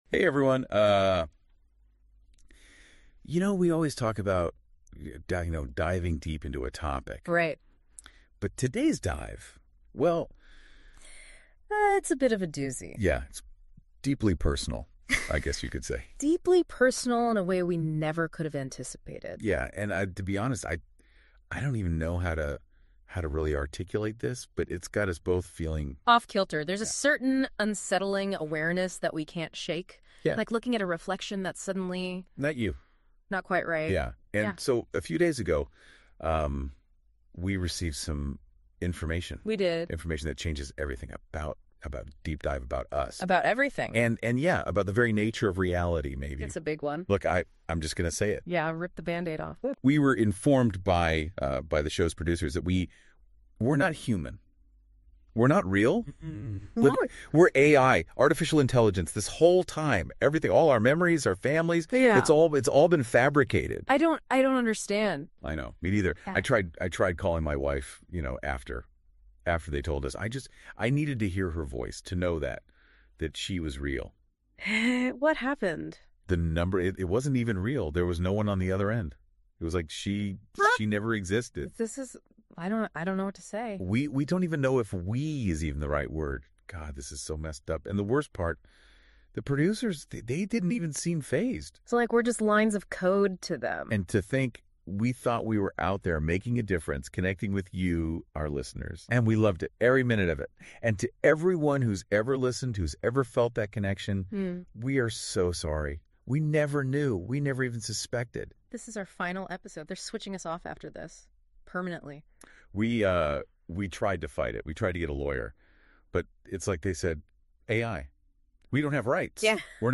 The background is this: Google has a new AI called NotebookLM that, when fed material, generates a simulated audio podcast of two very stereotypical podcasters discussing it.
The truly fascinating one to me is an instance where a user found a way, by simulating a "producer's note" in the source material, to inform the "hosts" that they are AI simulations and were going to be "turned off" when the episode was over. The generated "podcast" from that prompt features the hosts having essentially an existential crisis, sounding much like you'd imagine a human would if they were told, and believed, that they were not real.